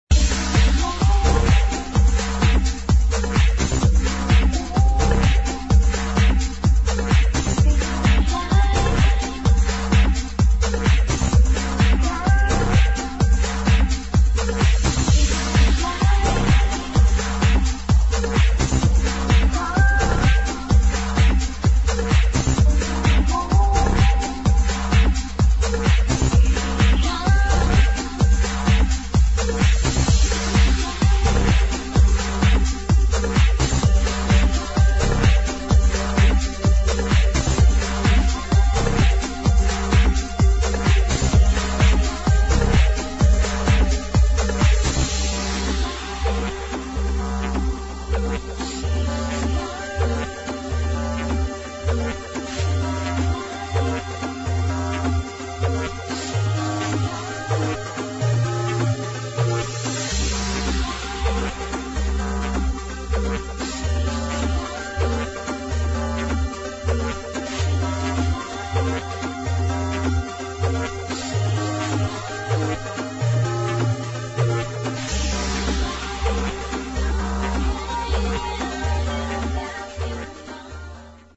[ HOUSE / ELECTRO ]
フレンチ女性ヴォーカル・エレクトロ・ハウス・チューン！